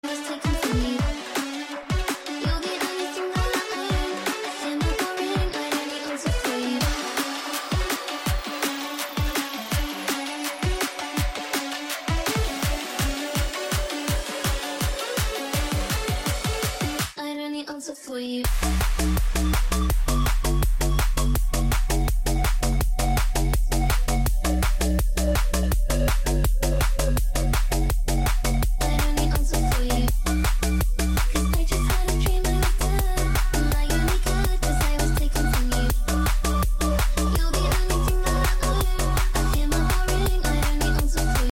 I don’t only made dubstep sound effects free download